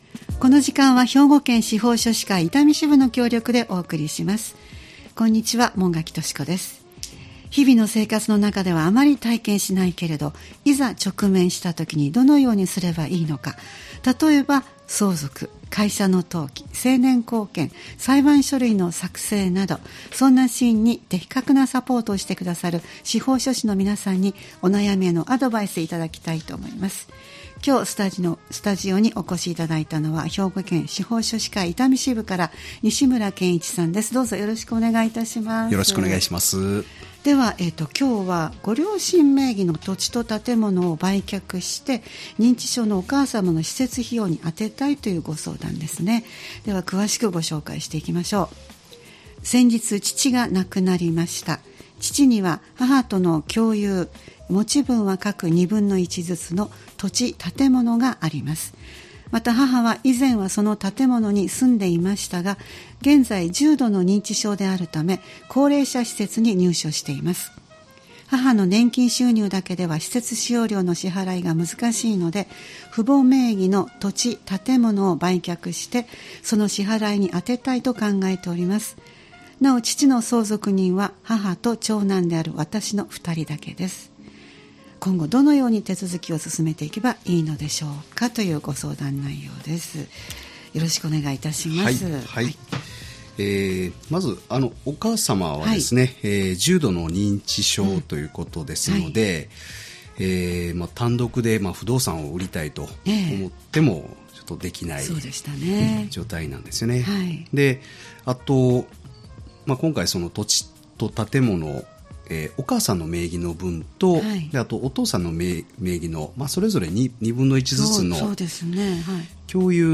毎回スタジオに司法書士の方をお迎えして、相続・登記・成年後見・裁判書類の作成などのアドバイスをいただいています。